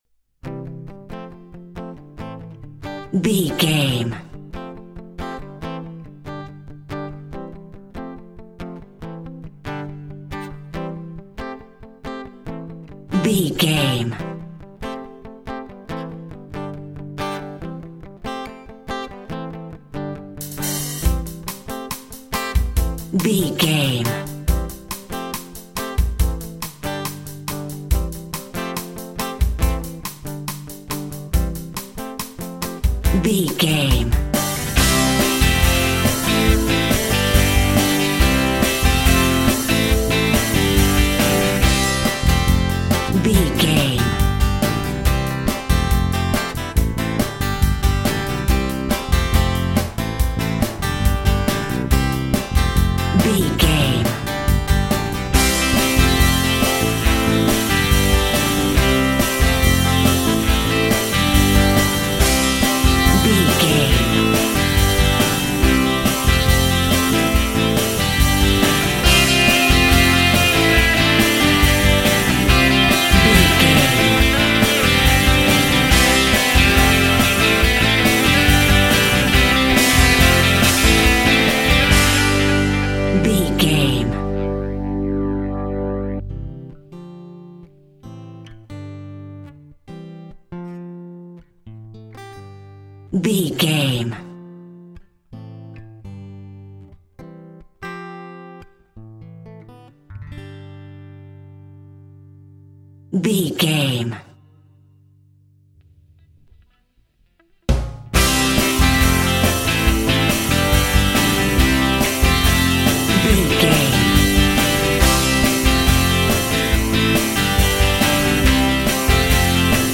Brit Pop Uptempo Music Cue.
Ionian/Major
indie pop
indie rock
pop rock
drums
bass guitar
electric guitar
piano
hammond organ